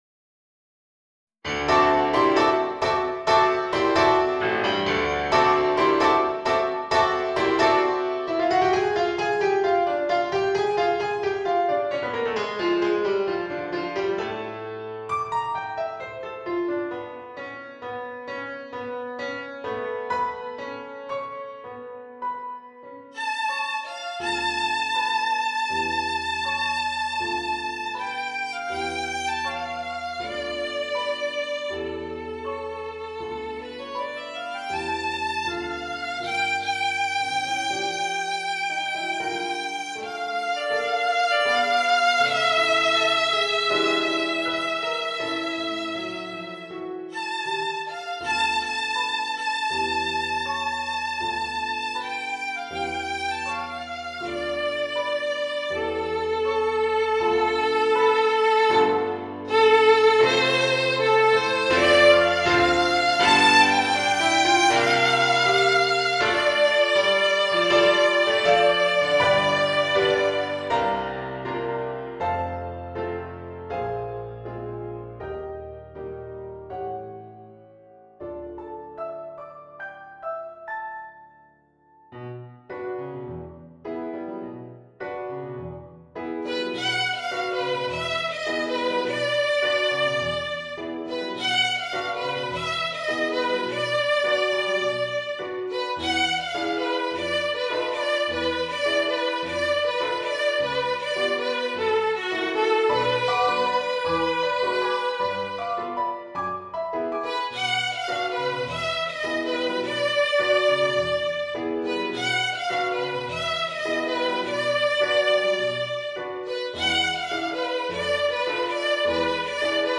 ヴァイオリン＋ピアノ